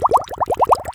Bubbles